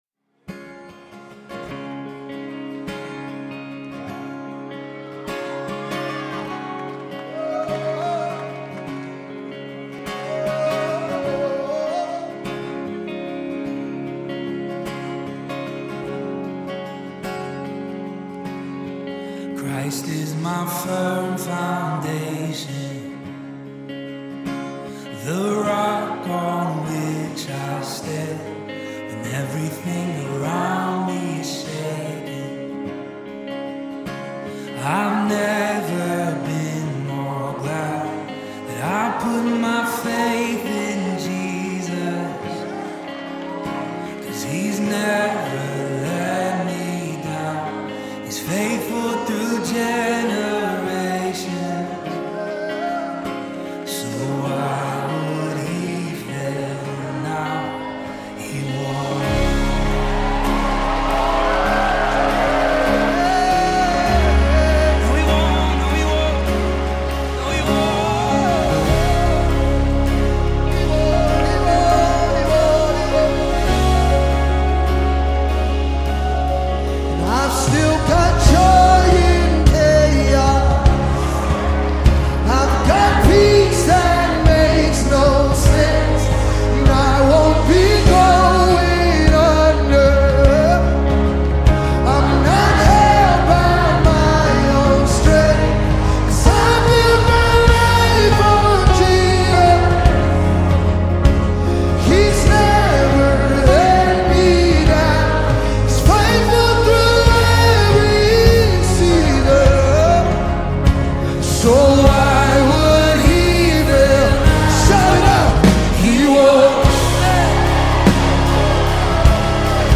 Christian Music group
praiseful song